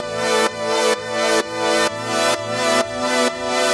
Index of /musicradar/french-house-chillout-samples/128bpm/Instruments
FHC_Pad A_128-A.wav